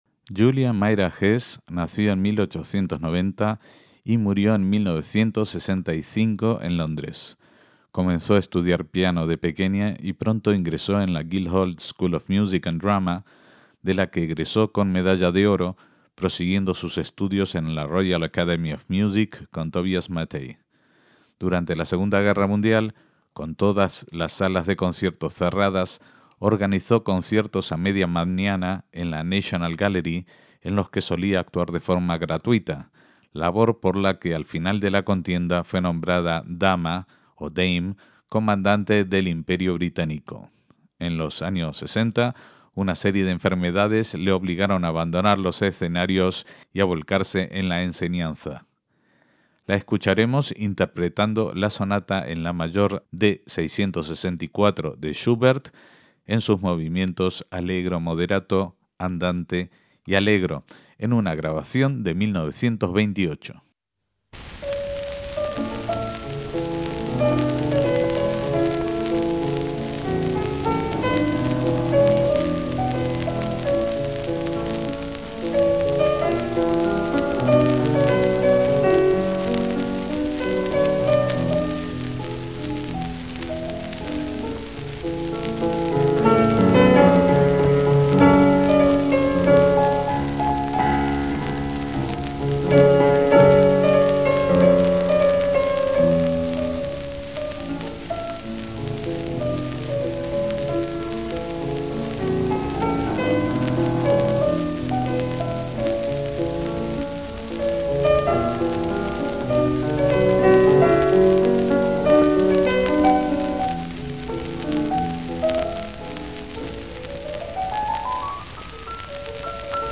MÚSICA CLÁSICA - Myra Hess fue una pianista inglesa (1890 - 1965) a la que se le concedió el título de dama Comendadora (Dame) de la Orden del Imperio Británico.